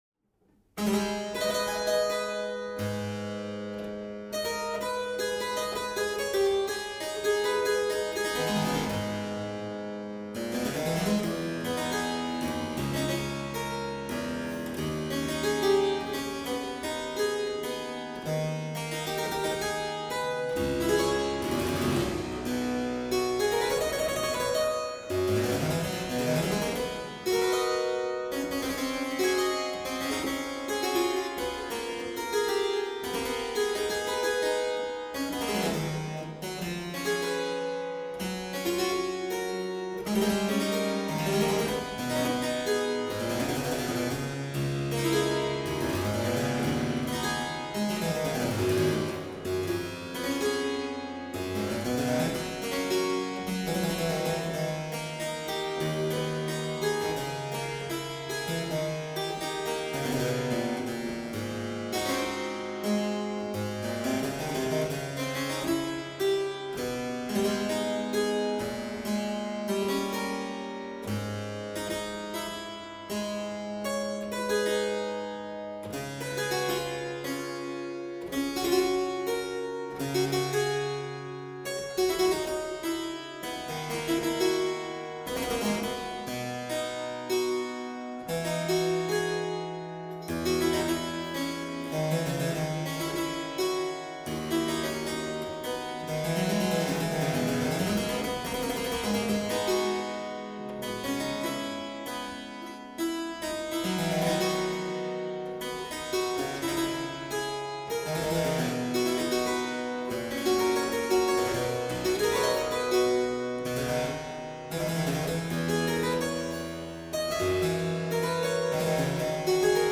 harpsichordist and conductor
Here I want to go into a Parvillian �riddle, wrapped in a mystery, inside an enigma.� * Number 144, the last piece but 5 in the MS, is an anonymous pr�lude non mesur� in a unique and otherwise unknown hand, according to Bruce Gustafson�s still-definitive catalog of 1977.